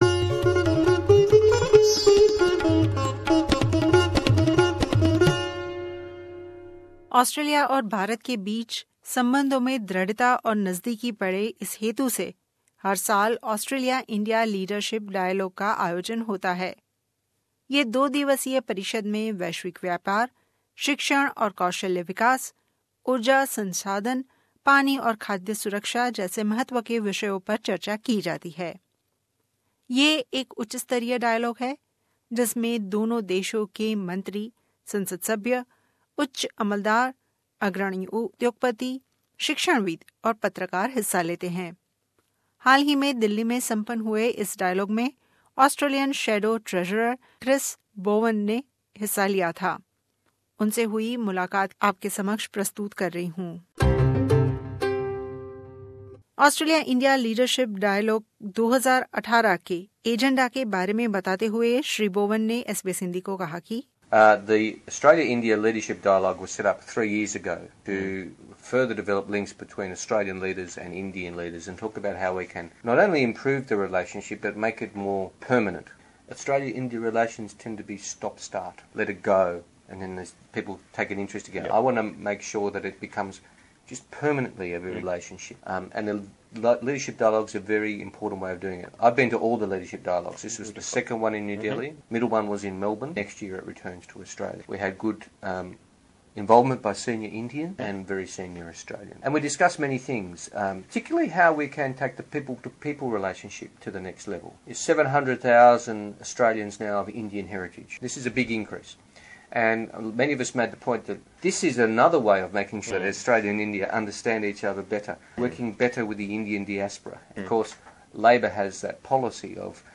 In an exclusive interview with SBS Hindi, Shadow Treasurer Chris Bowen shared the happening of Australia India Leadership Dialogue 2018. He also restated Labor's stand on the federal government's proposed citizenship changes.